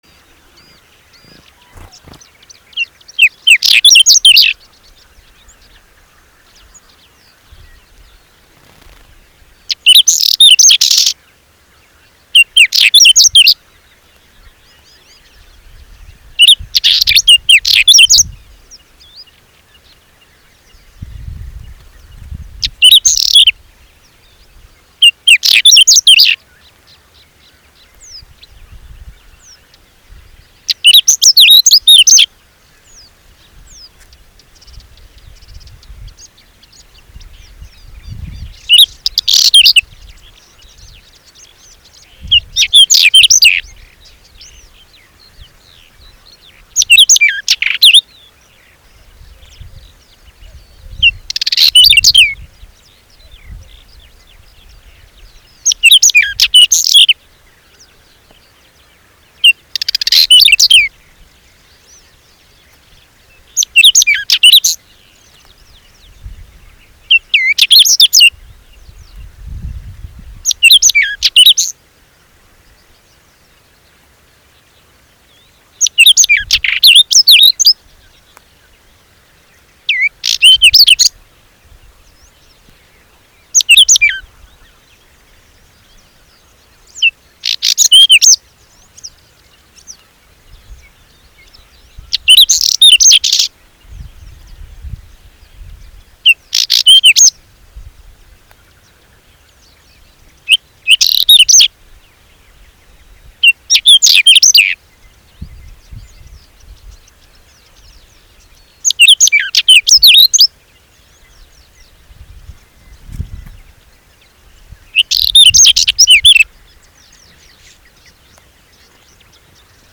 CALLS AND SONGS:
The Whinchat often gives a scolding “tek-tek” or “whu-tek-tek” used both as contact and alarm calls.
The song is short and fast, an abrupt warbling series of variable phrases, including melodious and scratchy notes, and some mimicry.
SAXICOLA-RUBERTA-.mp3